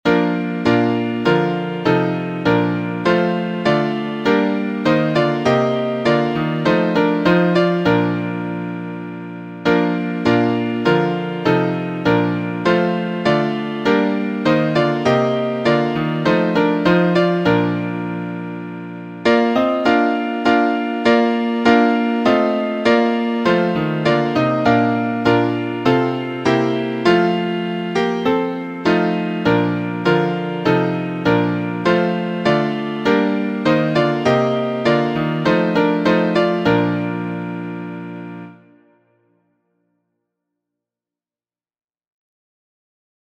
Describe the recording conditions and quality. MP3 Midi